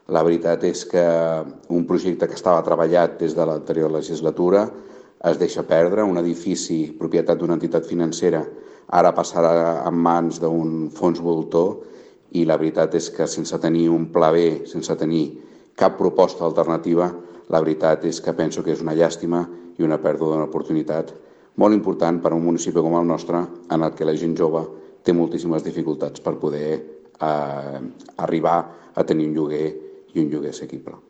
Per a Prades, “es tracta d’un immens error per part de l’actual govern” i assegura que, des de la seva formació, estan “molt decebuts” per la decisió que ha adoptat l’administració que governa Miquel Bell-lloch. El cap de files socialista també lamenta perdre l’edifici on s’anaven a ubicar els 14 habitatges, ja que diu que ara passarà a estar “a mans d’un fons voltor”: